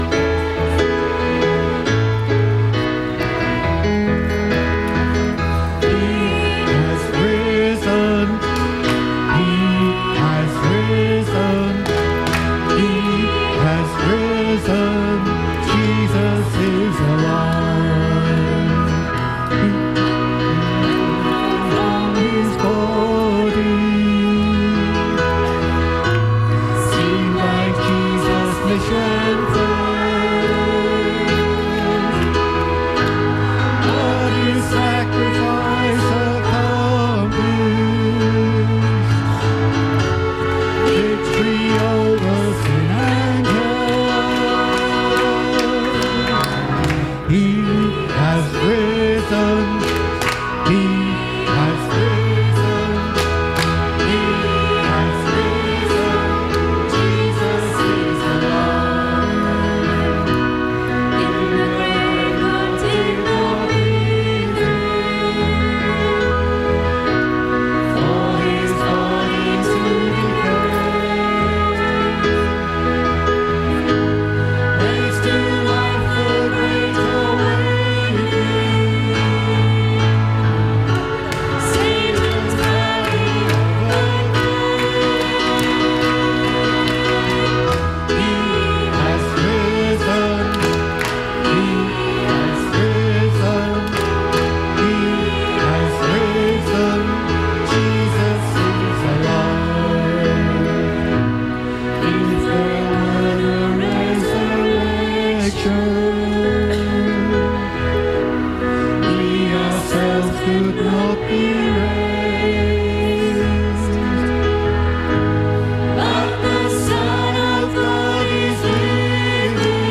A Praise and Worship evening with the SBC worship team.